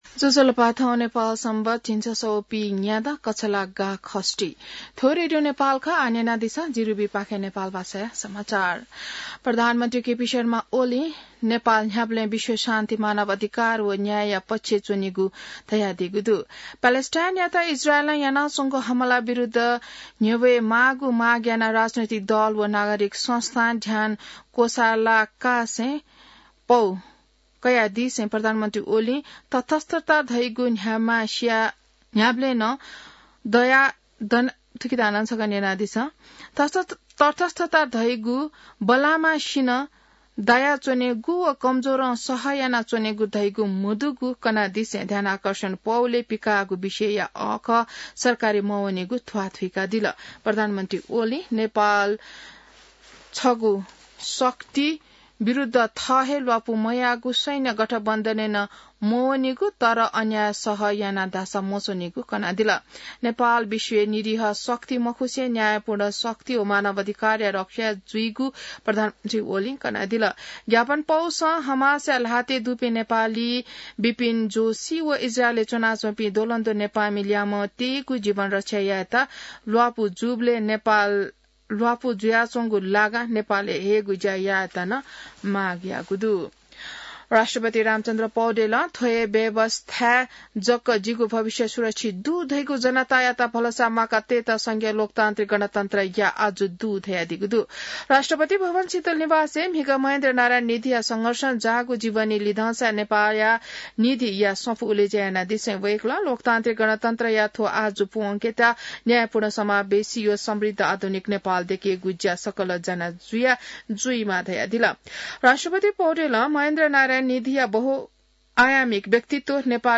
नेपाल भाषामा समाचार : ७ मंसिर , २०८१